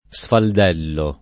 sfaldellare v.; sfaldello [ S fald $ llo ]